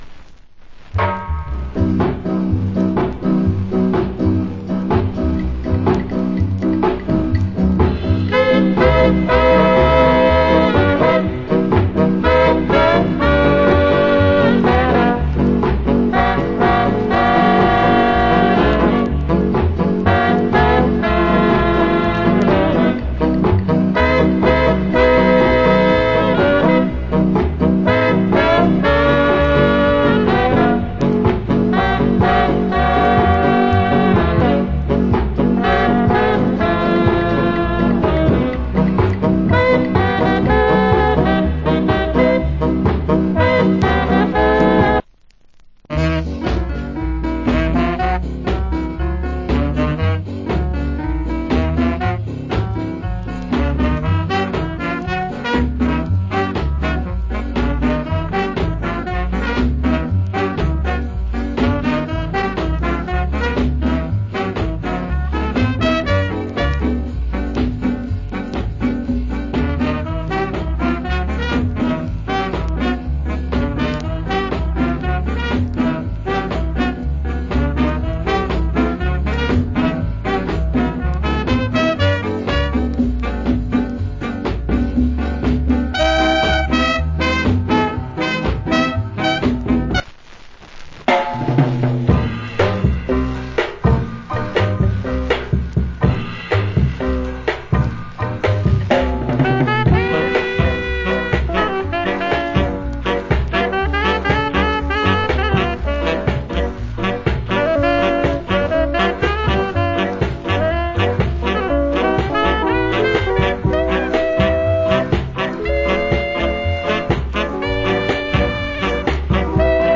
Nice Ska Inst. 90's Press.